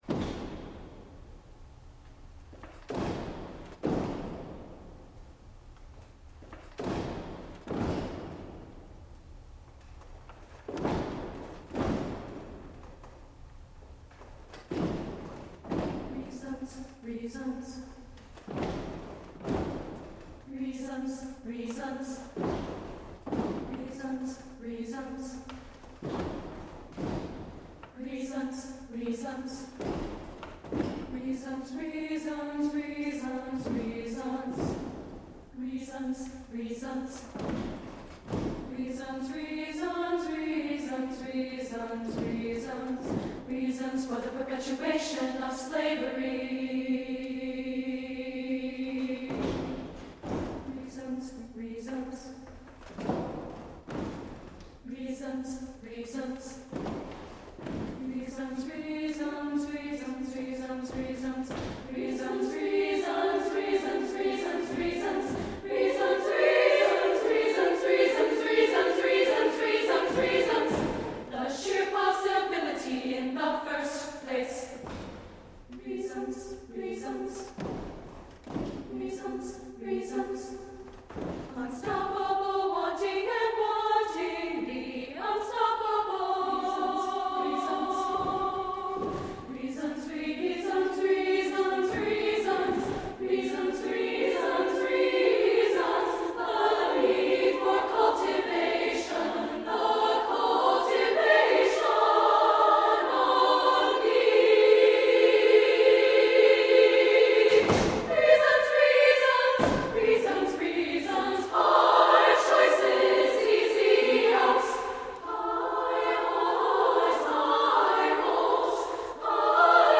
SSAA a cappella